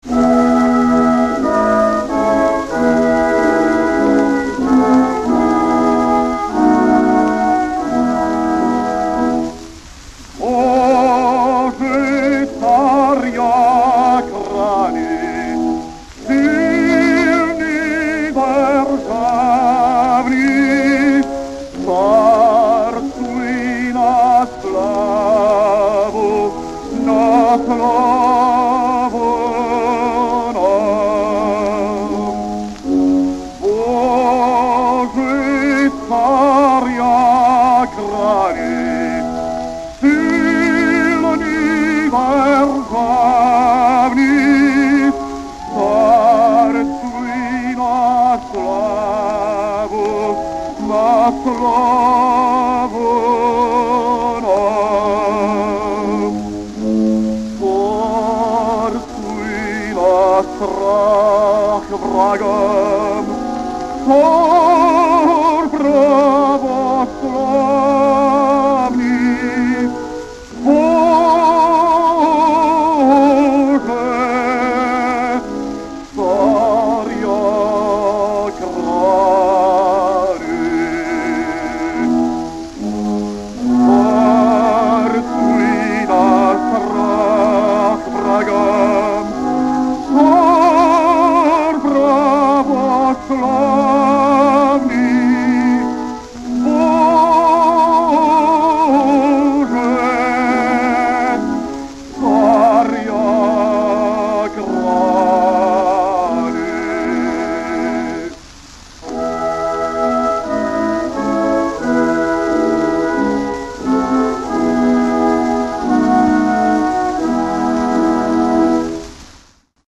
Марсель Журне (1867-1933г.) французский оперный певец (бас) .Известный своими выступлениями в оперных спектаклях лучших театров мира.В Ла Скала исполнял ведущие партии под руководством самого Артуро Тосканини.